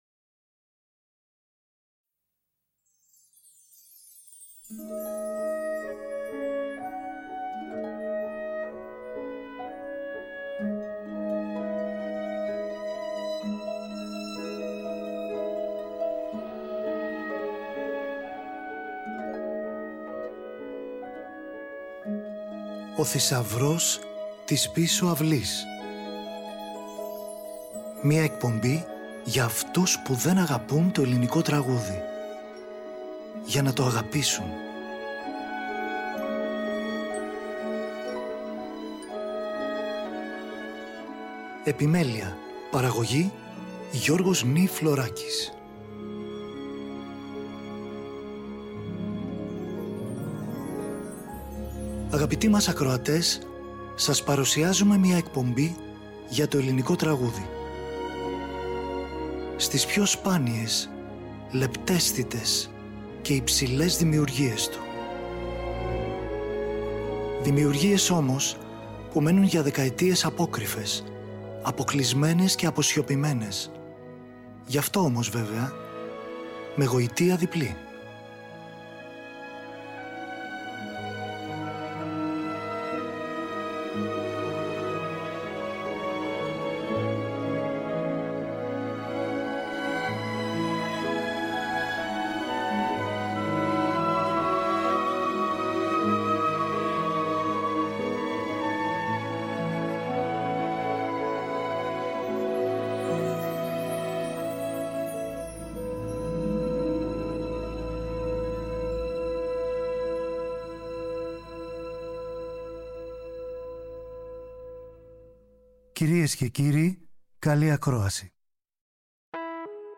σόλο πιάνο